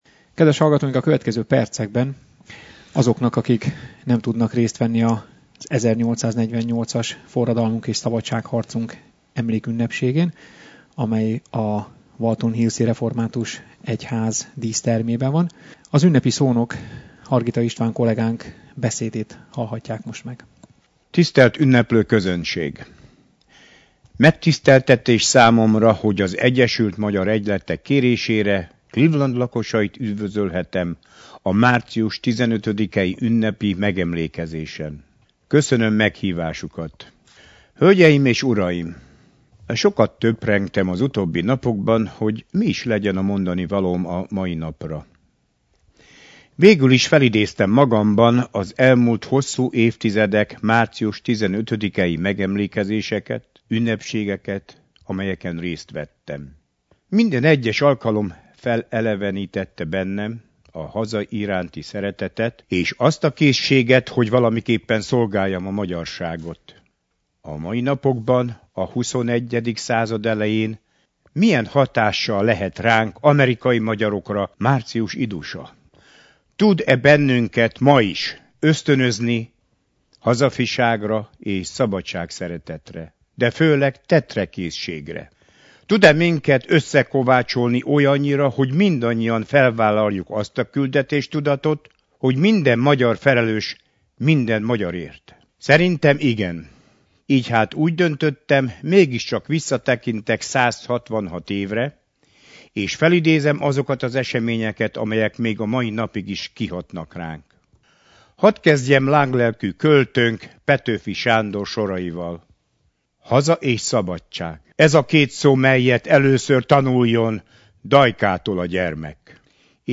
Az Egyesült Magyar Egyletek szervezésében vasárnap az Első Magyar Református Egyházban emlékezett meg az 1848-as forradalom és szabadságharc 166 évfordulójáról az ohioi magyarság.